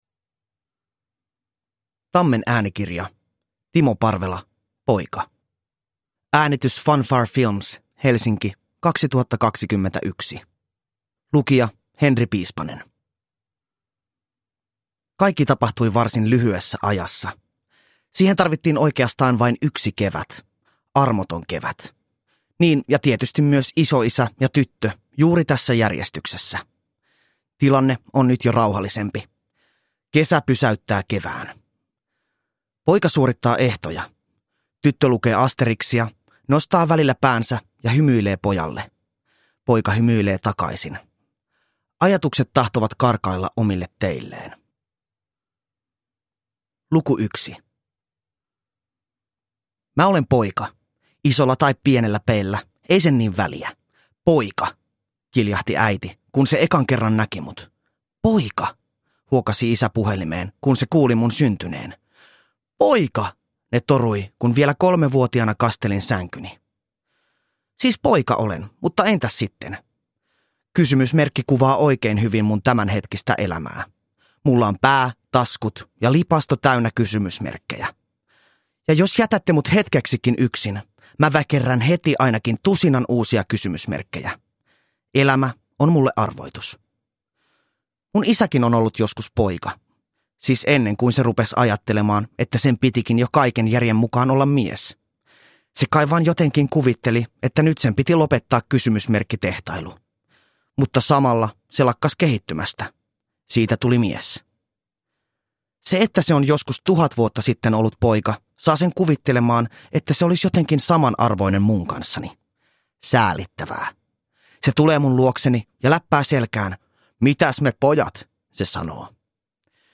Poika – Ljudbok – Laddas ner